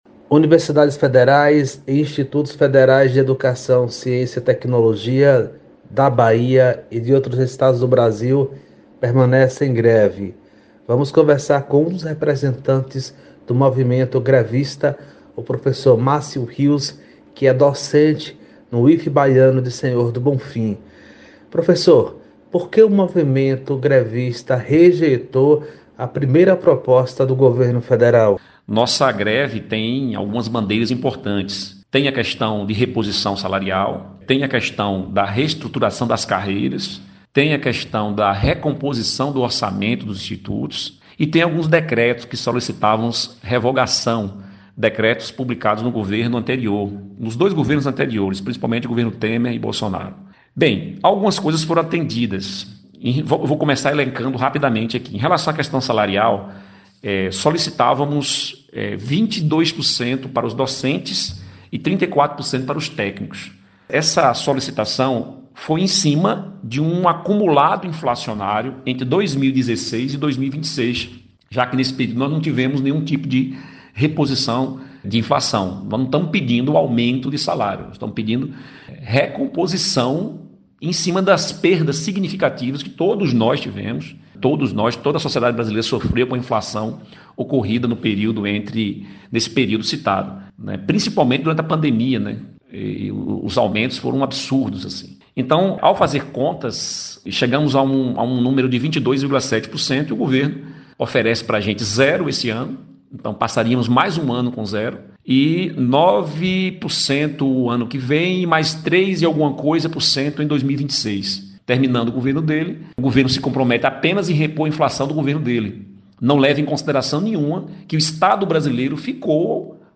Representantes do movimento da greve do IFBaiano/Bonfim com informações sobre as negociações